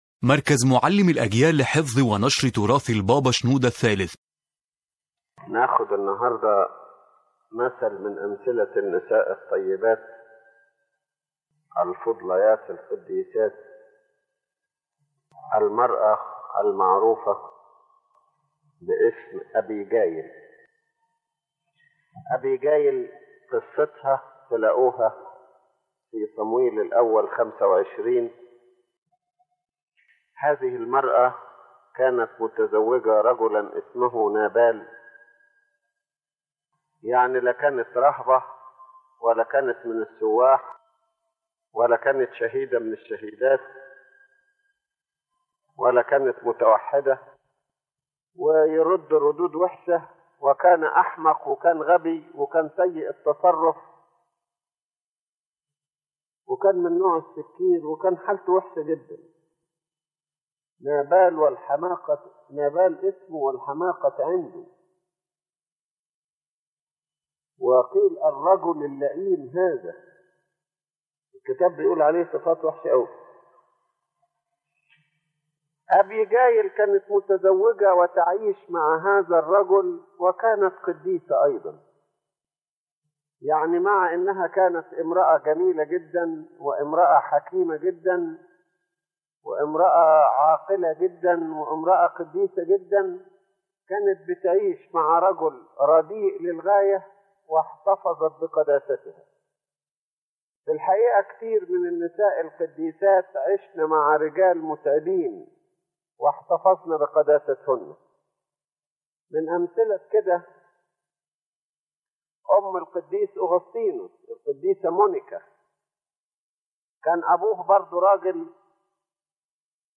The lecture revolves around the character of Abigail as a model of a wise and holy woman who lived in difficult circumstances, yet preserved her holiness and acted with wisdom and love, becoming a cause of salvation and peace for those around her.